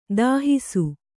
♪ dāhisu